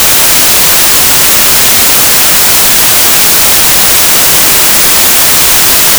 Visualisierung und Auralisierung der Ergebnisse
cavity1-62mic.wav